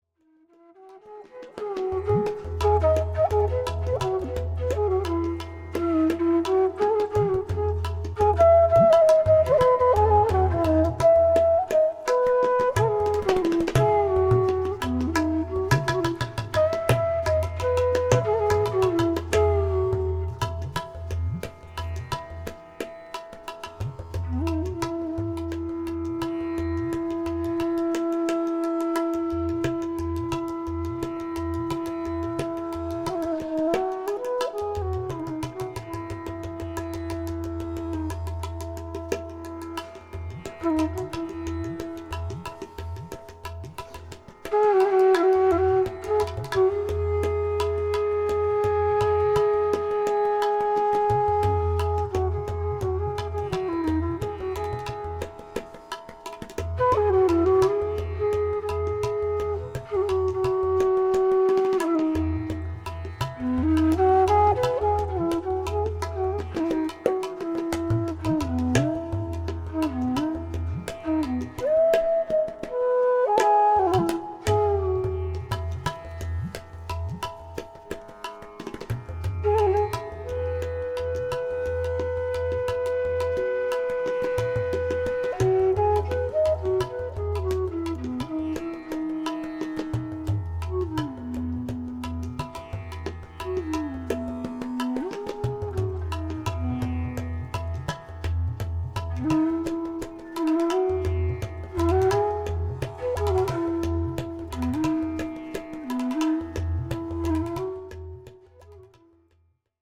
Contemplative bansuri melodies
tabla
Genre: North Indian Classical.
Teen Tal (16)   13:08